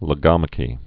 (lə-gŏmə-kē)